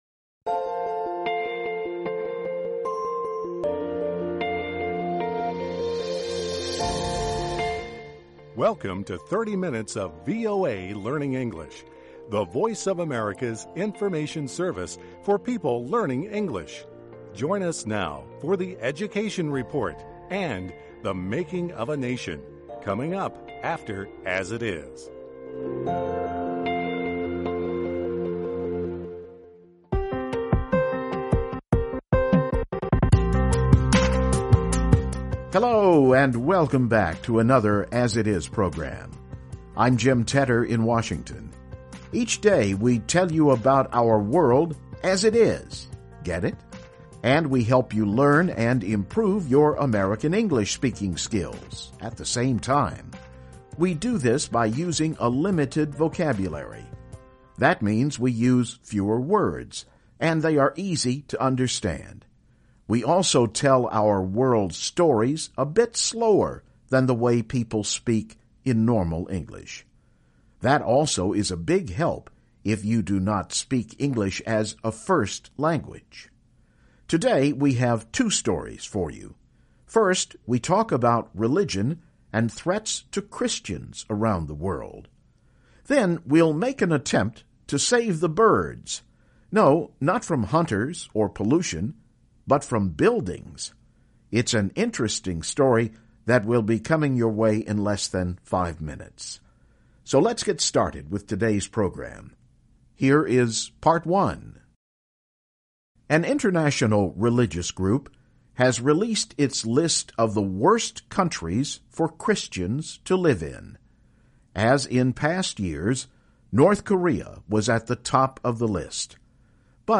Learning English Broadcast